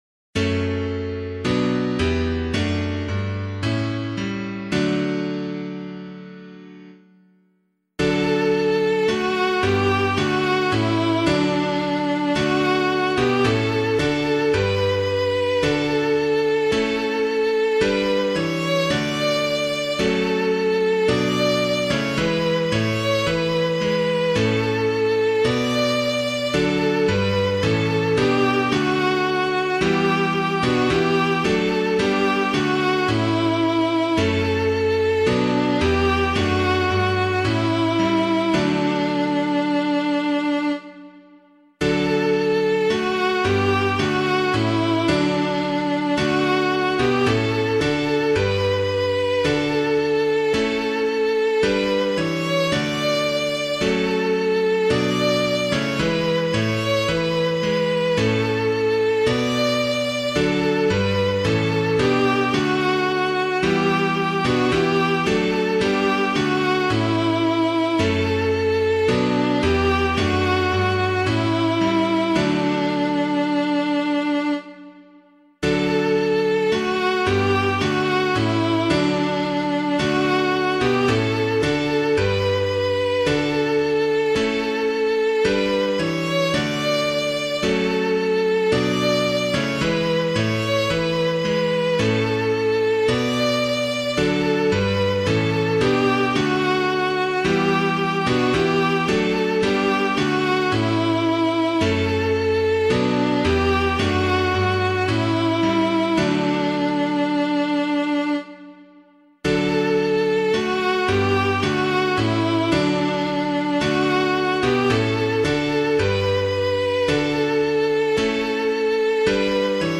piano
Lord of the Living in Your Name Assembled [Kaan - CHRISTE SANCTORUM] - piano.mp3